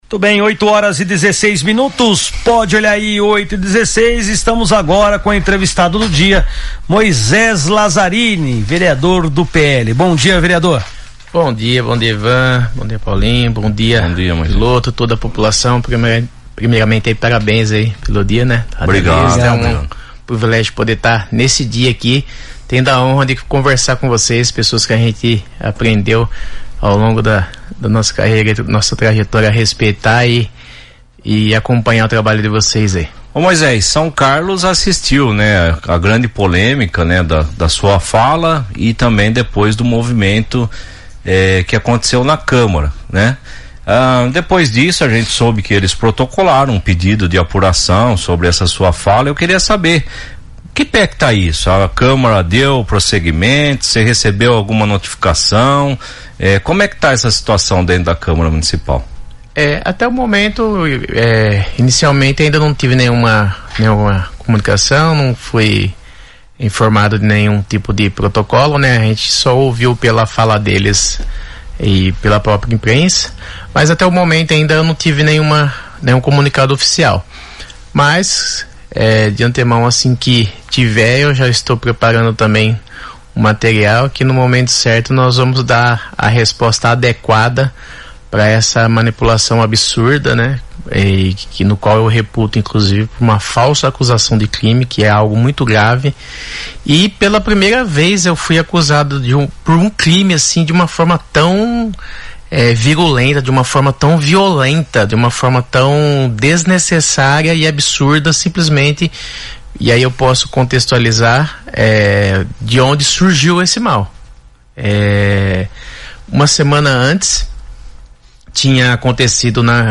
Em entrevista na São Carlos FM, Moisés Lazarine afirma ser alvo de “manipulação política” |